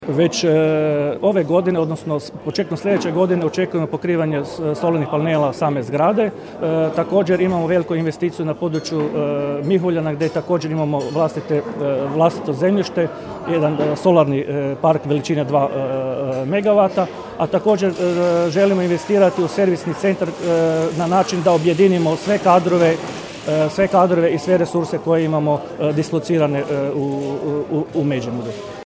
Međimuje plin izborna skupština, Čakovec 17.11.2021.